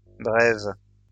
来自 Lingua Libre 项目的发音音频文件。 语言 InfoField 法语 拼写 InfoField brèves 日期 2021年6月3日 来源 自己的作品